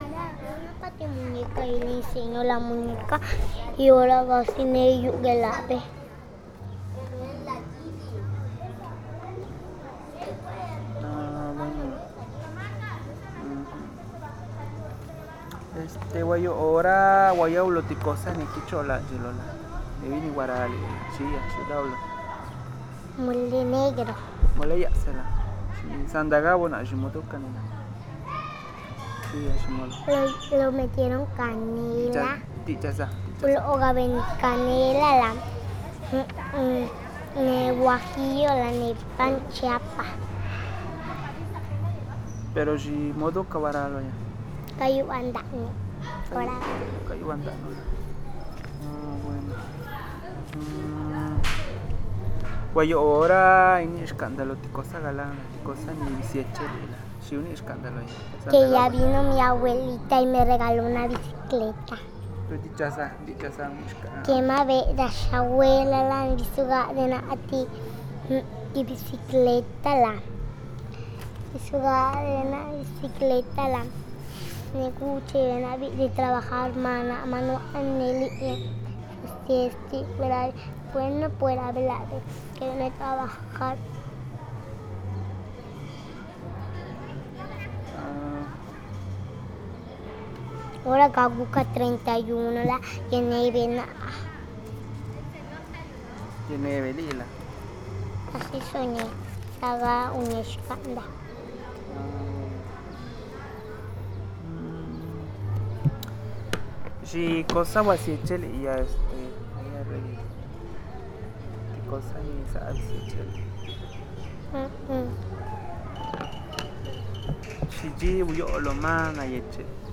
Localidad: San Blas Atempa, San Blas Atempa, Oaxaca
Tipo: conversacional